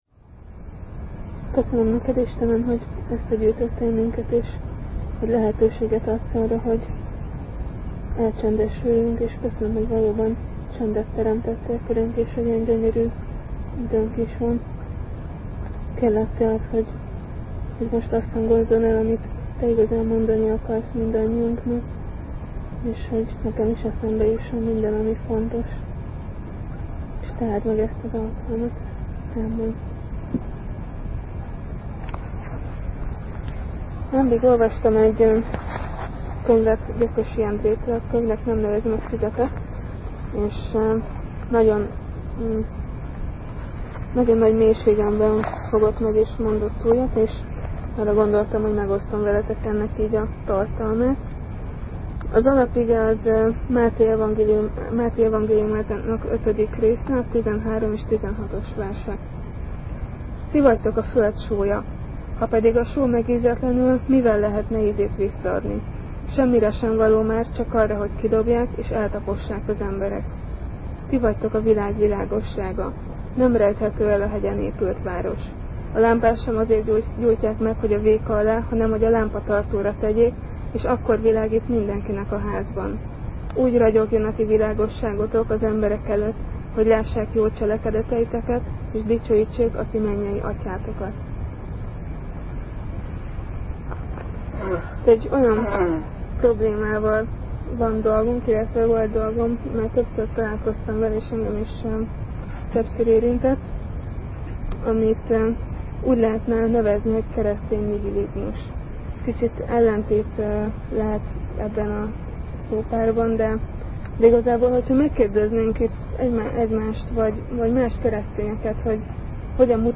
A felvétel helyenkénti rosszabb min?ségéért elnézéseteket kérjük.